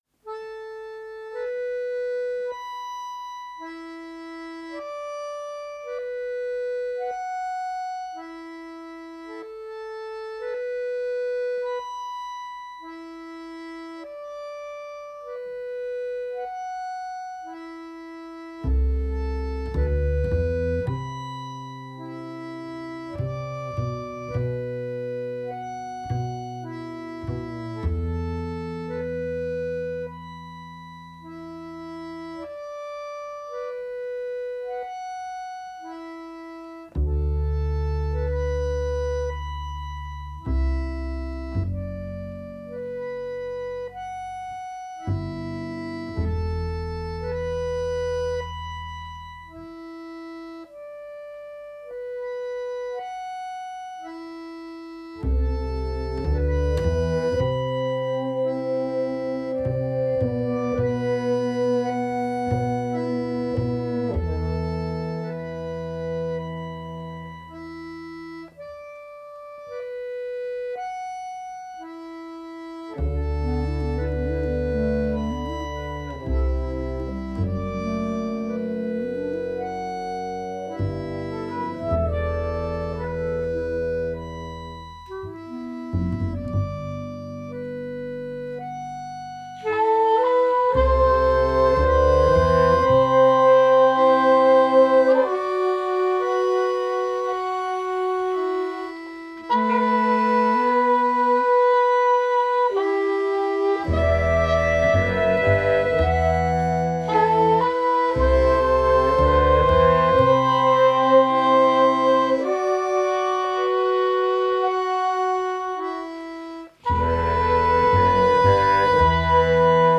Genre: Klezmer, Balkan, Gypsy Jazz, Worldbeat